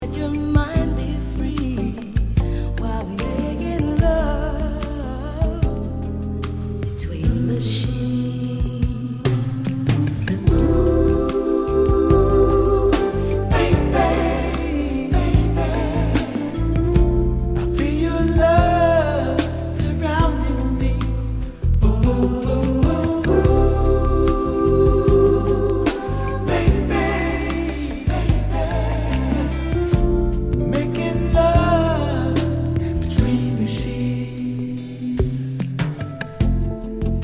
Tag       R&B R&B